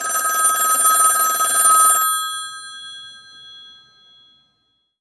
- Added a phone ringing sound effect to be used later. 2025-08-24 04:54:21 -04:00 863 KiB Raw History